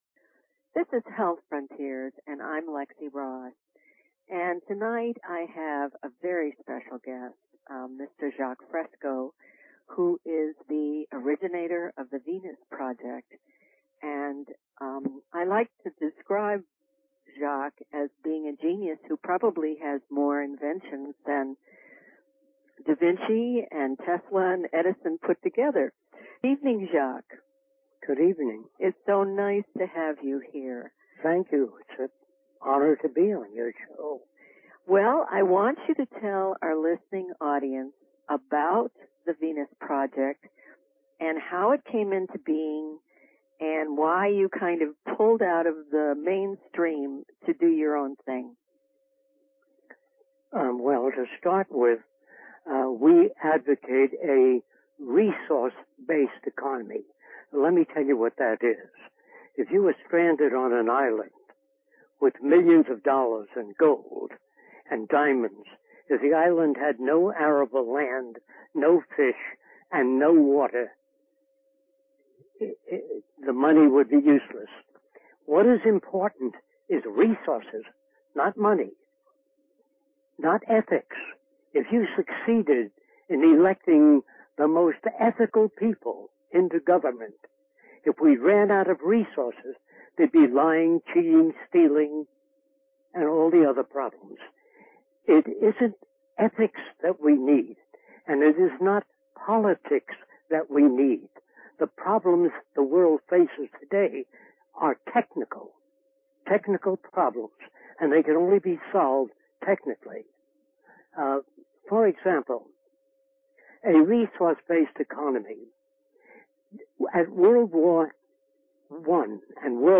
Talk Show Episode, Audio Podcast, Health_Frontiers and Courtesy of BBS Radio on , show guests , about , categorized as
Guest: Jacque Fresco, astounding visionary, inventor and social engineer who has created more inventions than Edison, Tesla and Da Vinci put together.